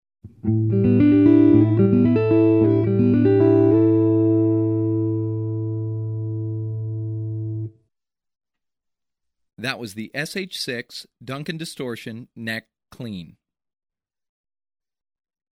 Duncan Distortion, SH-6n: halspositie, clean sound Audio Unknown
sh_6_duncan_distortion_neck_clean.mp3